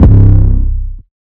thwump808.wav